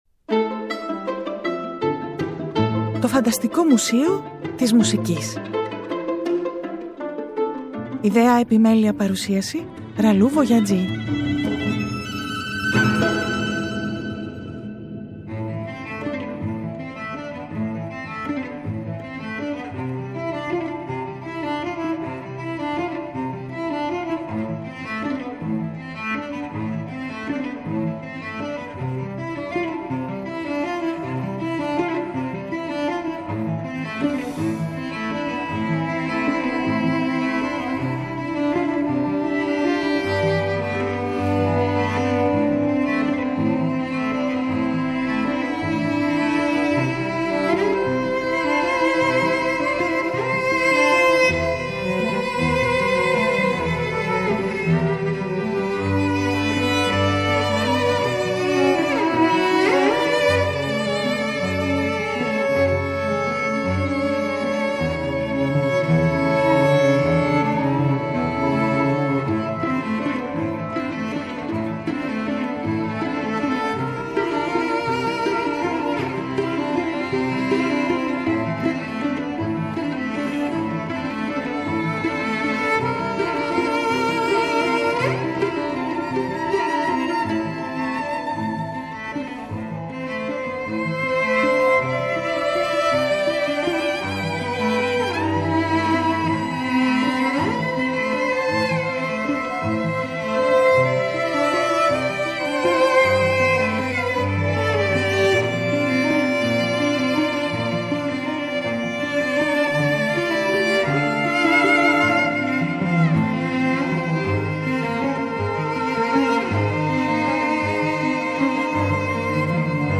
Το 2005 στην Ιταλία ηχεί η γη και ο αέρας ή μήπως ο αέρας έρχεται από τους γιους του Αιόλου στον 17ο αιώνα; Kύματα σκέψεων σε ένα τραγούδι χωρίς λόγια γεμάτο πάθος και συναίσθημα διασχίζουν ηπείρους το 2020… είναι ορισμένες από τις στάσεις μας σε μια ξενάγηση στη μουσική με ελεύθερους συνειρμούς με αφορμή Γη – Αέρα.